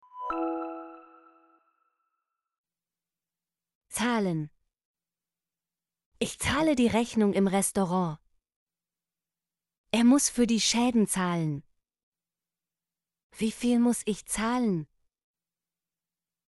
zahlen - Example Sentences & Pronunciation, German Frequency List